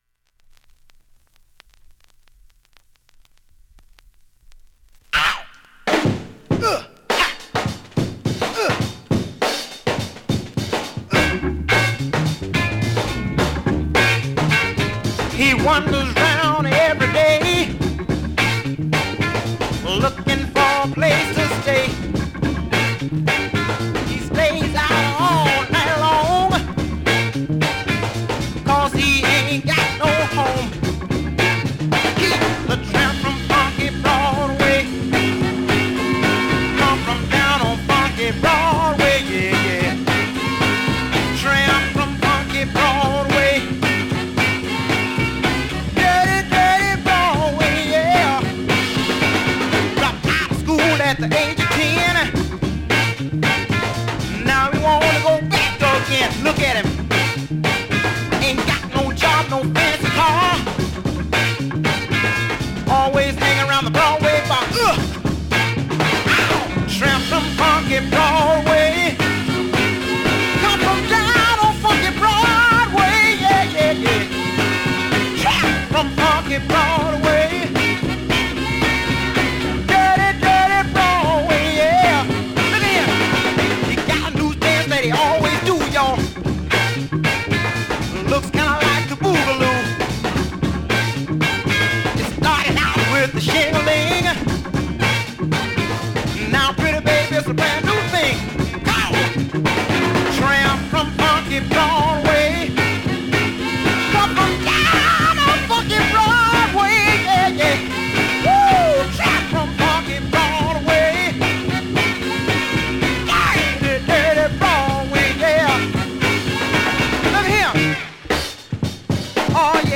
現物の試聴（両面すべて録音時間５分４秒）できます。
2:32 「FUNK 45'S」掲載 厚紙白ジャケット